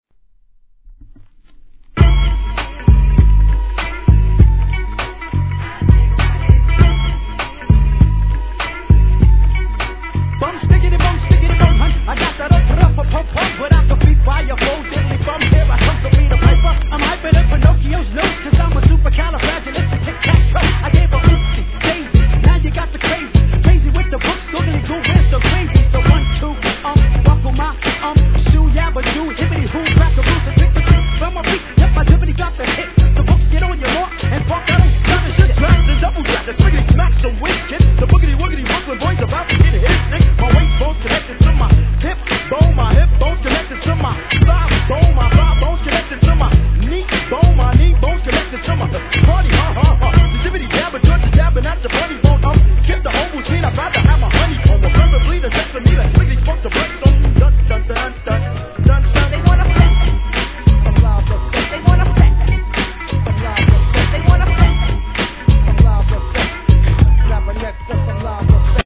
HIP HOP/R&B
個性的なMICリレーで圧巻のデビューCLASSIC!!